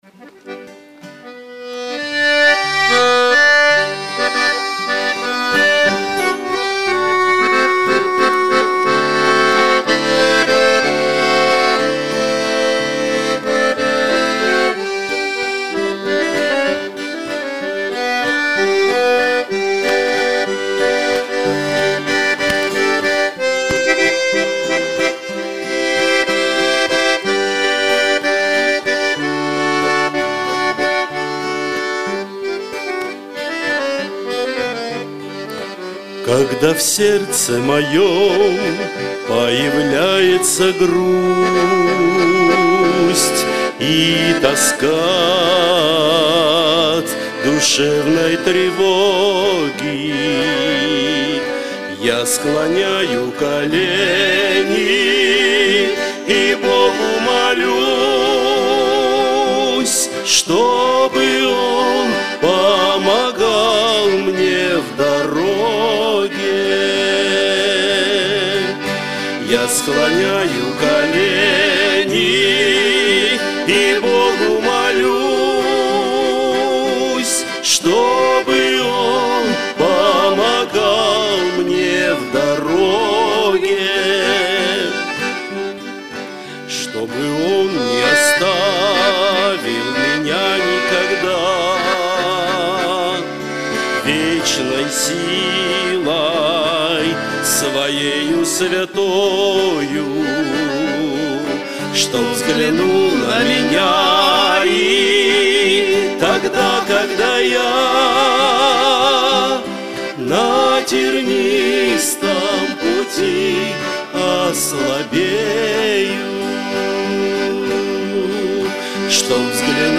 Богослужение 27.10.2024
Когда в сердце моем - Источник Жизни (Пение)[